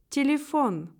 La syllabe accentuée a une prononciation plus longue et plus appuyée que les autres.
телефон [i][7]